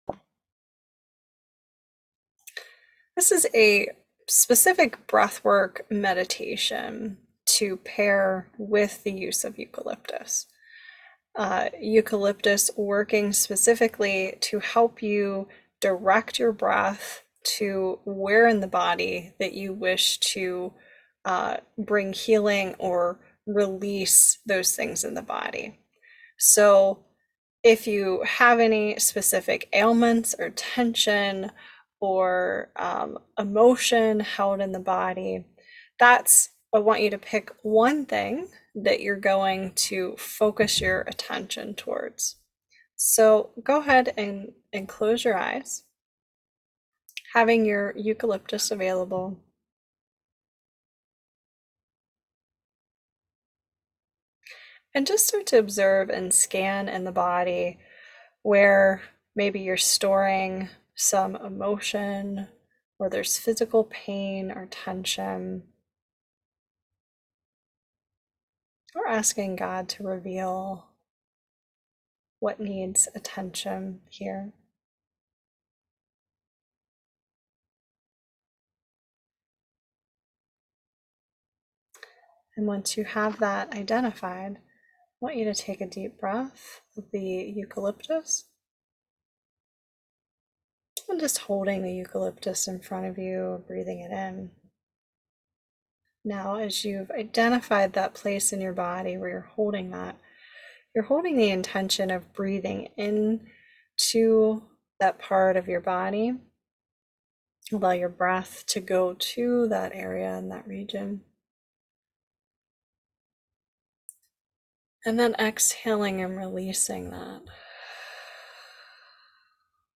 Cleansing Breathwork Meditation - With Eucalyptus 6 Min